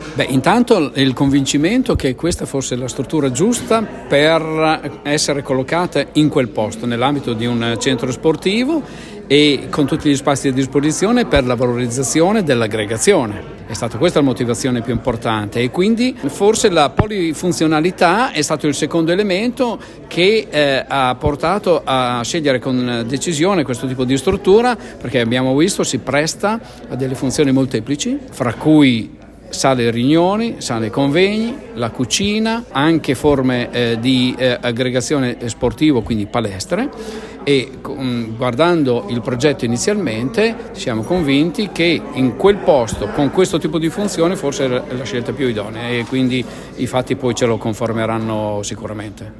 Al nostro microfono il sindaco di Finale Emilia, Claudio Poletti, a proposito degli elementi fondamentali che hanno portato alla realizzazione di questo importante progetto: